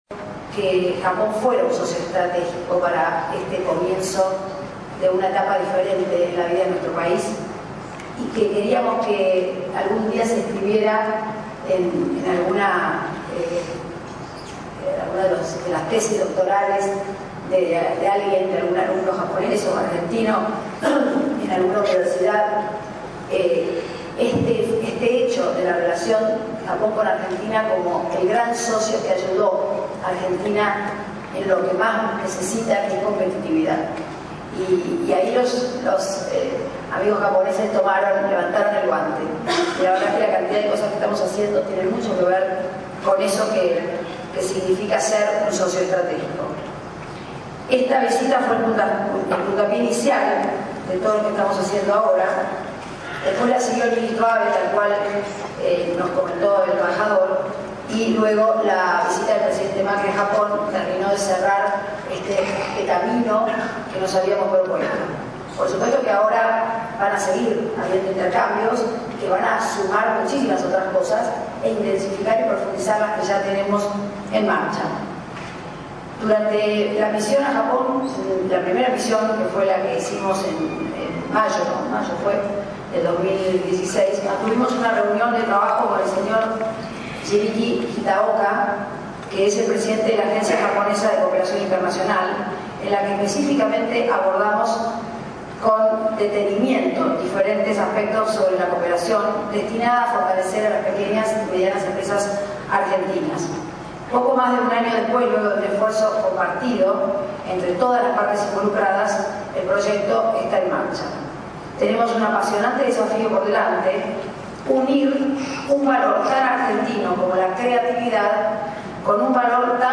En las bellísimas  instalaciones del Palacio San Martín conservadas y cuidadas como en sus orígenes  el miércoles 25  de octubre se lanzó oficialmente el proyecto “Red de Asistencia Técnica para Oportunidades Globales de Kaizen”, denominado  “Kaizen-Tango”